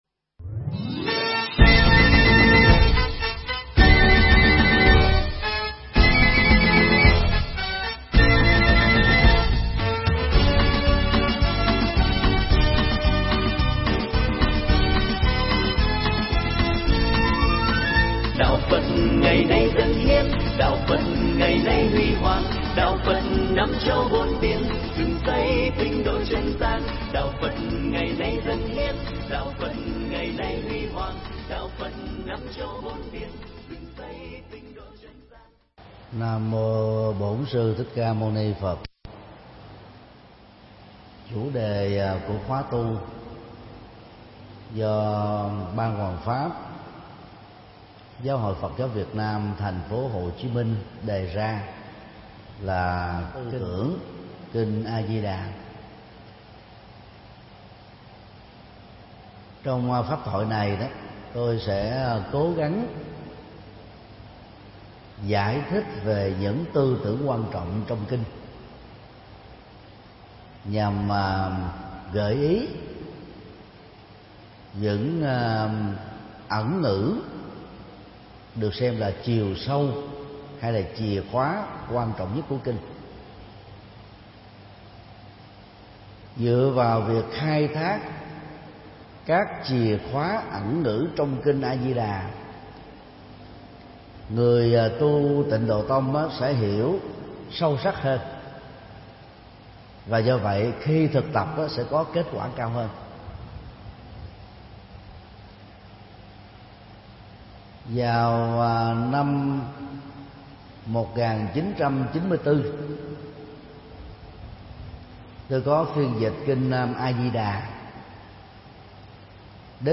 Mp3 Thuyết Giảng Tư Tưởng Kinh A Di Đà
giảng tại chùa Phổ Quang